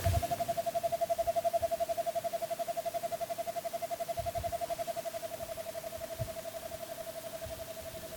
Nome científico: Megascops hoyi
Nome Espanhol: Alilicucú Yungueño
Nome em Inglês: Yungas Screech Owl
Localidade ou área protegida: Tuneles de la Merced
Condição: Selvagem
Certeza: Fotografado, Gravado Vocal
alilicucu-yungas.mp3